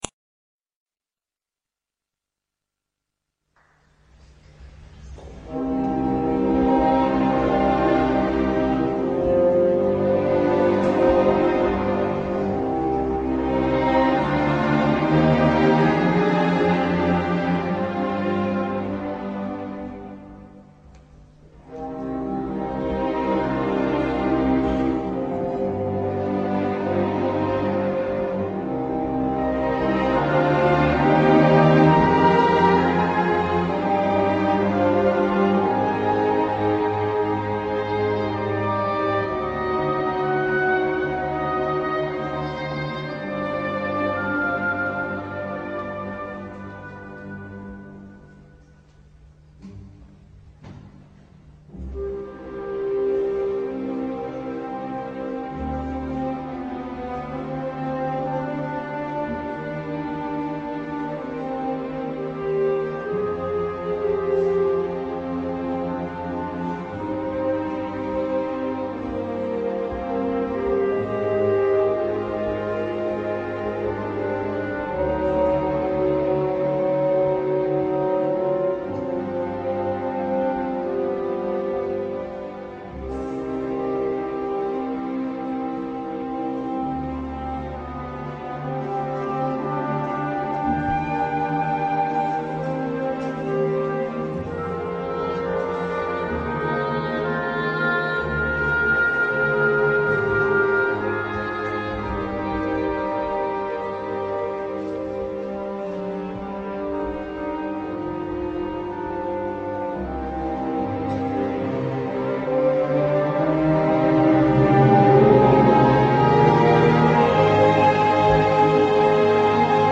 unknown-classic.mp3